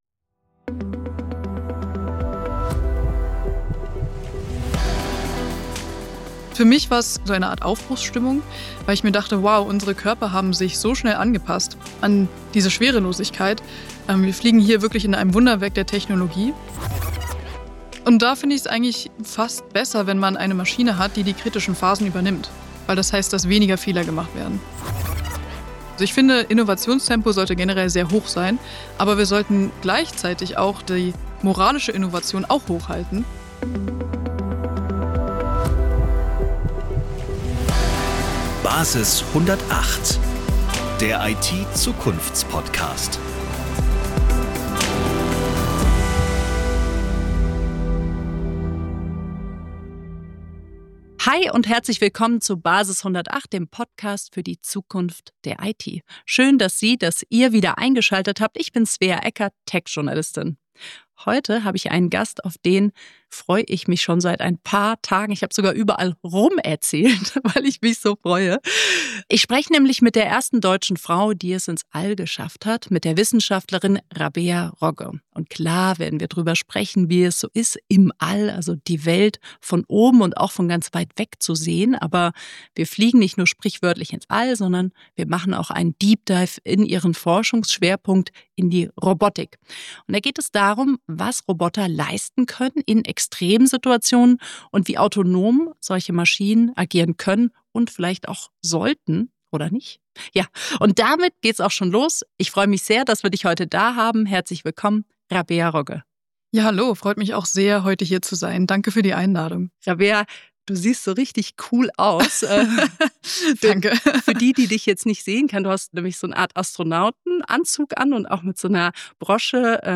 Gemeinsam tauchen die beiden tief in Themen wie autonome Systeme, KI in kritischen Situationen, Robotik in der Forschung, moralische Verantwortung, Dual-Use-Herausforderungen und die Frage, wie Mensch und Maschine in Zukunft zusammenarbeiten, ein. Rabea Rogge berichtet von ihrem Astronautinnentraining, den eindrucksvollsten Momenten im All und davon, welche Rolle Roboter künftig auf der Erde, auf See und im Weltraum spielen werden.